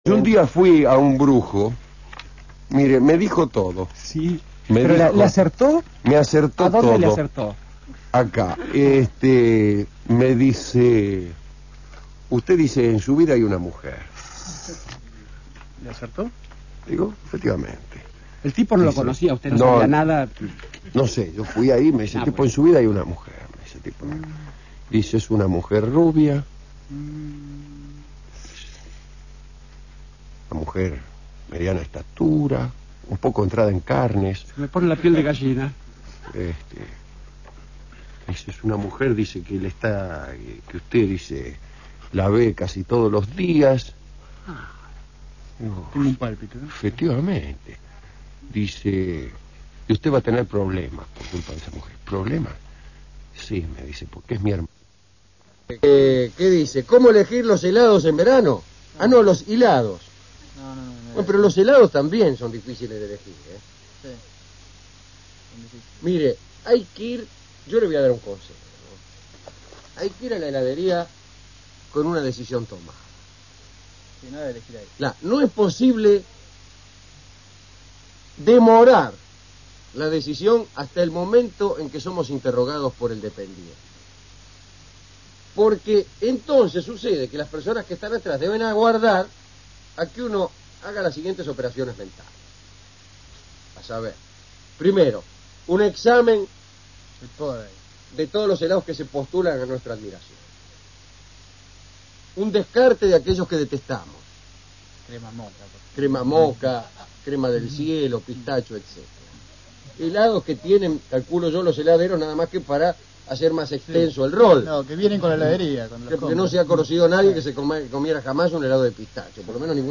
Estudios de Radio Nacional (AM 870 kHz), fechas desconocidas